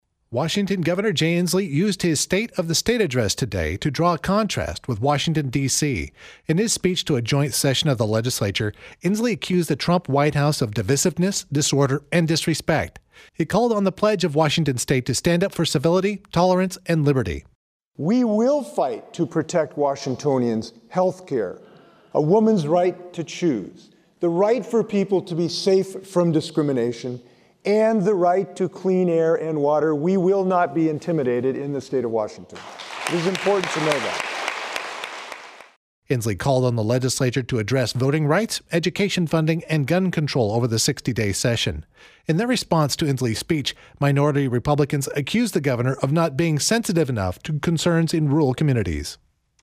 Washington Gov. Jay Inslee delivered his State of the State address to a joint session of the Legislature Tuesday.
WEB-INSLEE-STATE-OF-STATE.mp3